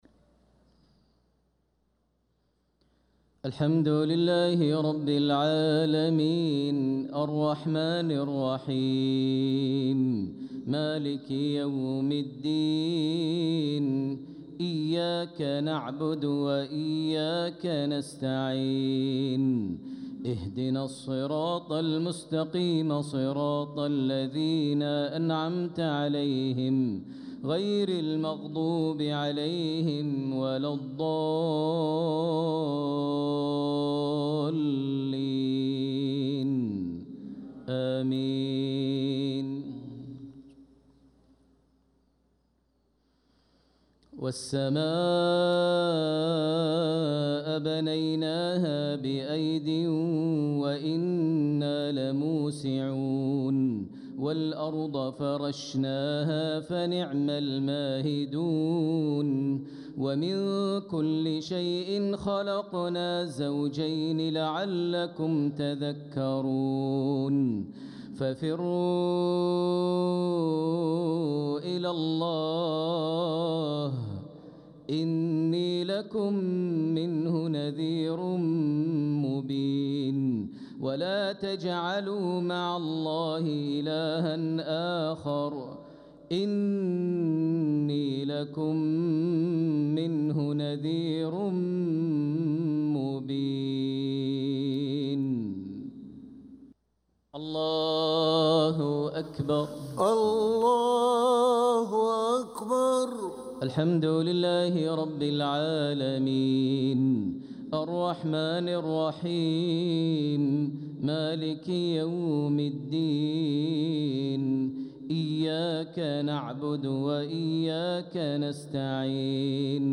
صلاة المغرب للقارئ بندر بليلة 16 صفر 1446 هـ
تِلَاوَات الْحَرَمَيْن .